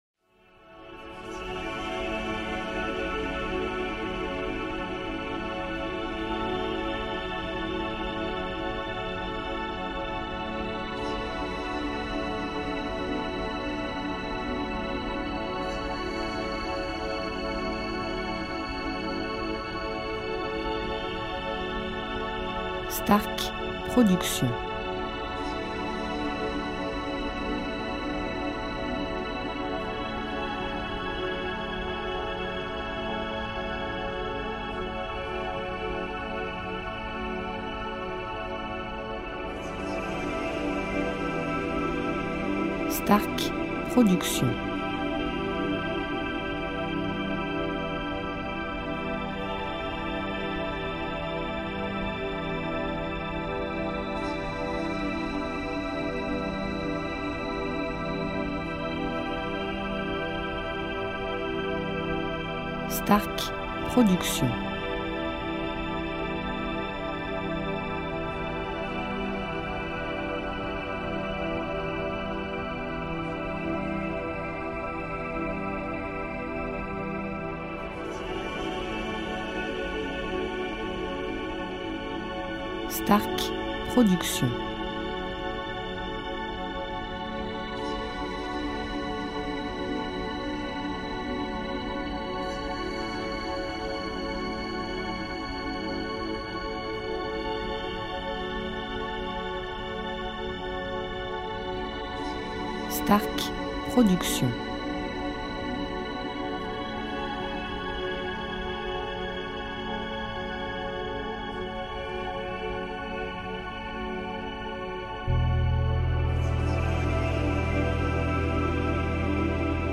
style Sophrologie Méditation Relaxant durée 1 heure